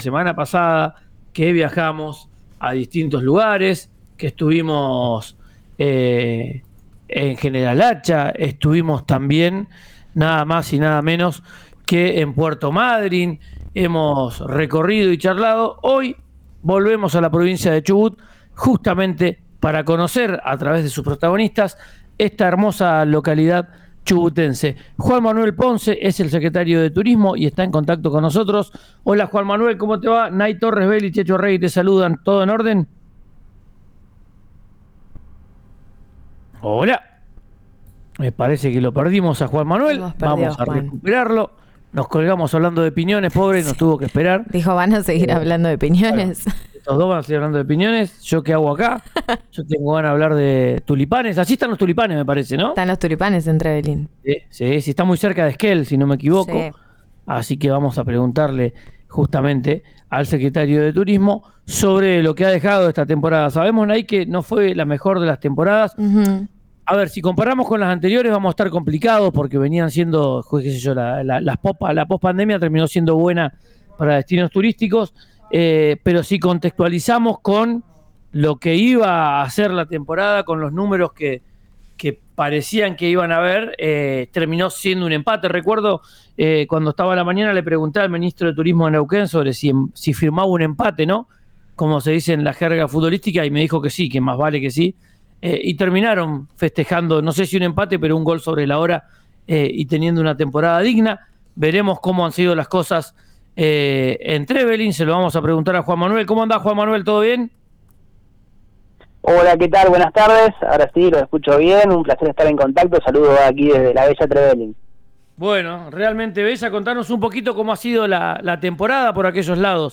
Escuchá a Juan Manuel Peralta, secretario deTurismo de Trevelin, en RÍO NEGRO RADIO: